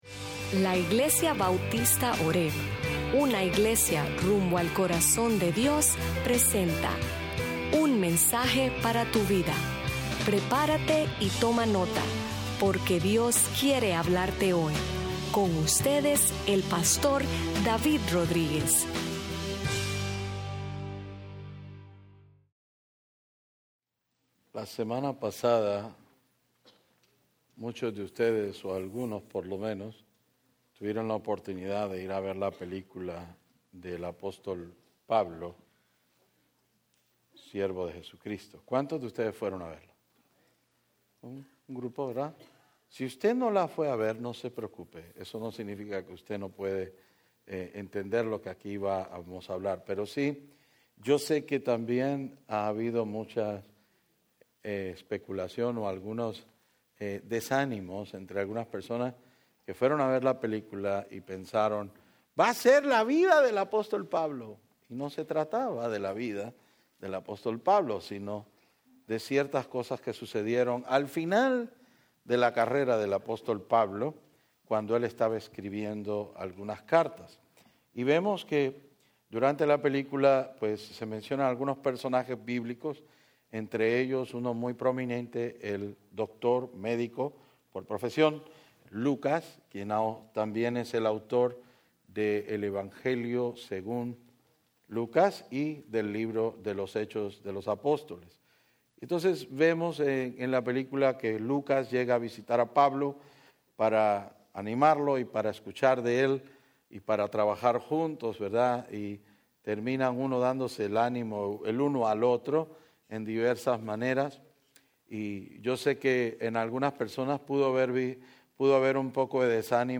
Sermons Archive - Page 100 of 140 - horebnola-New Orleans, LA